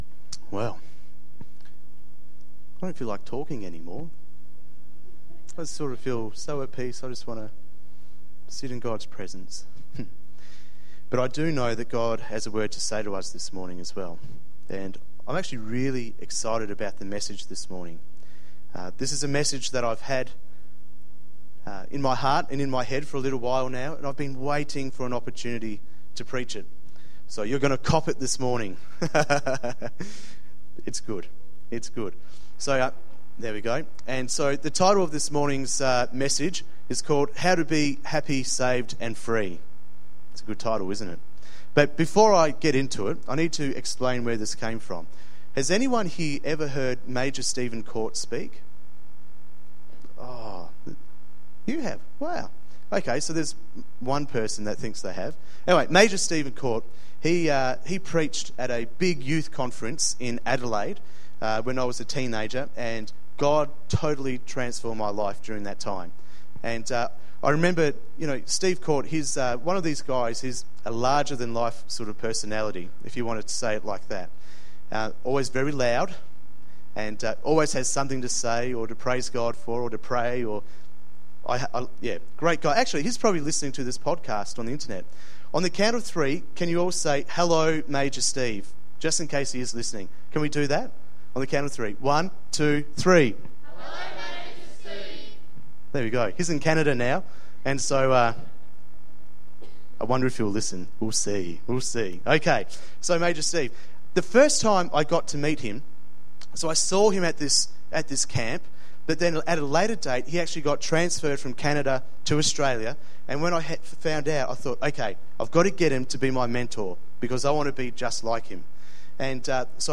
A four point sermon on how you can be Happy, Saved and Free.